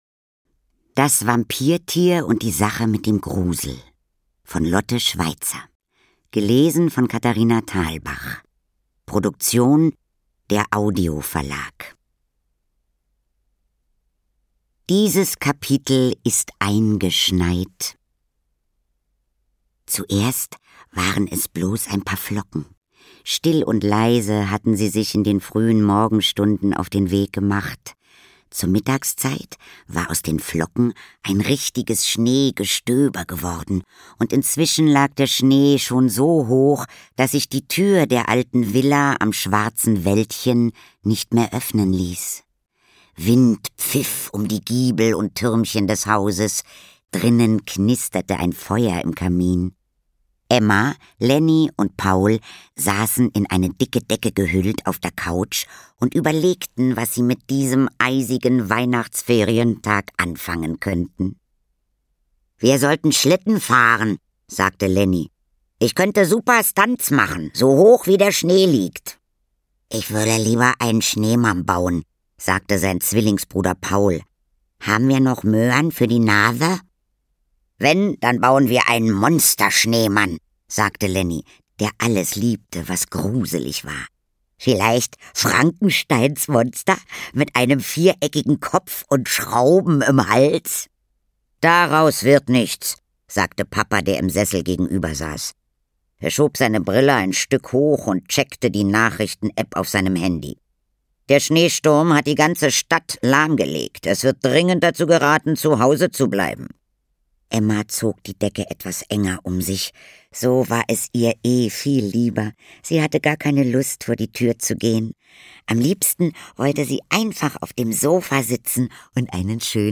Ungekürzte Lesung mit Katharina Thalbach (2 CDs)
Katharina Thalbach (Sprecher)